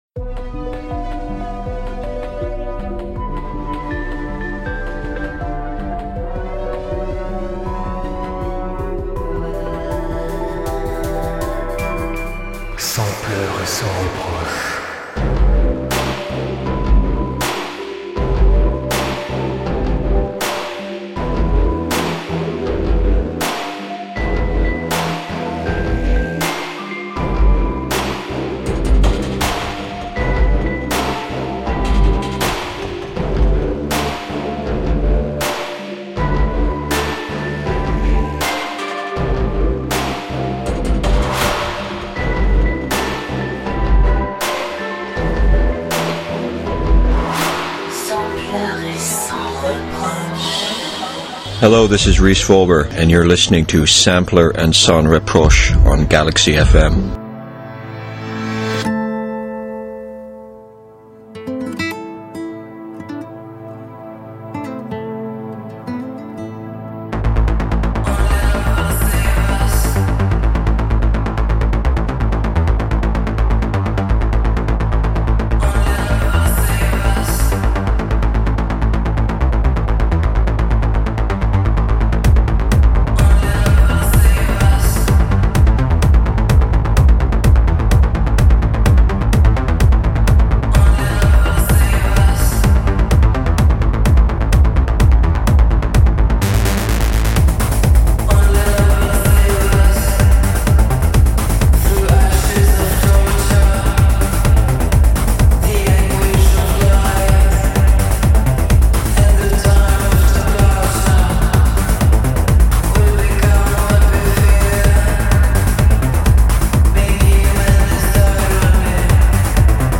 (Radio broadcast)
EBM - DARKTECHNO - INDUSTRIEL & RELATED MUSIC